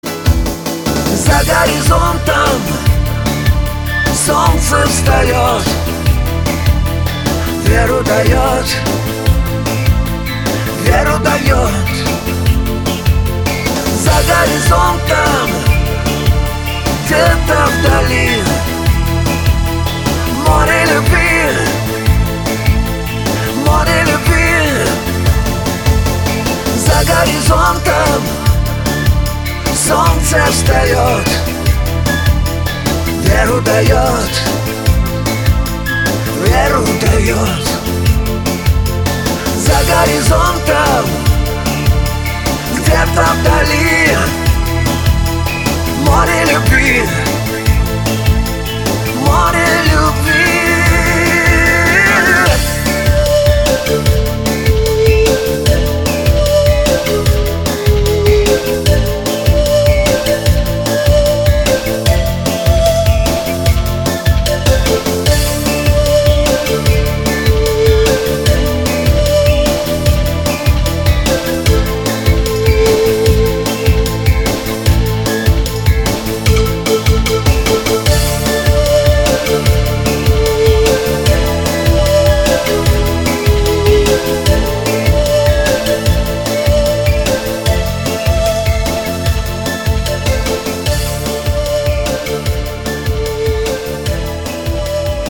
• Качество: 192, Stereo
русский шансон
духовые